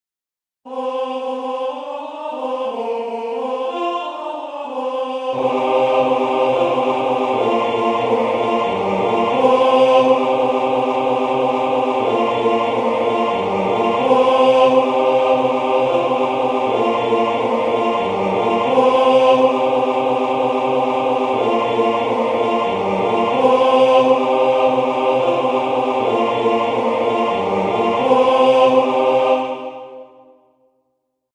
Ноты, партитура голосов, хор
Прослушать НОТЫ (2 куплета):
Русская народная песня.